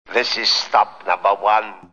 Computer Sounds